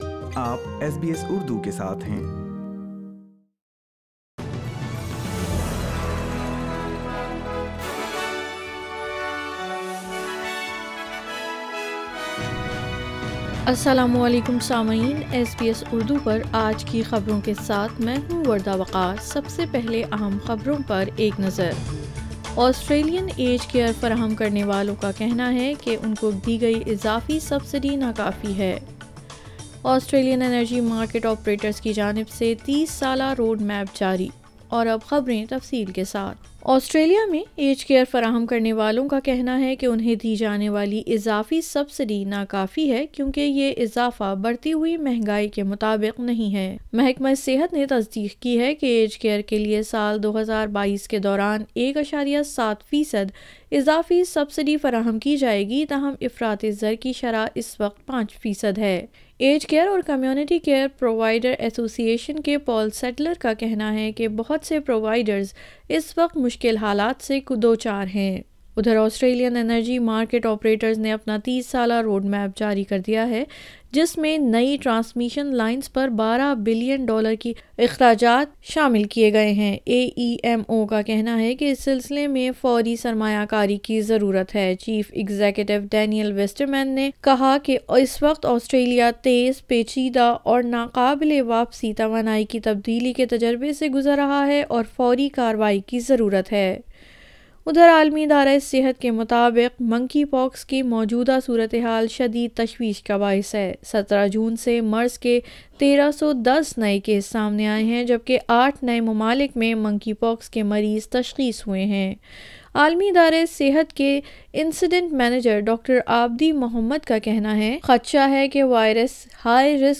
SBS Urdu News 30 June 2022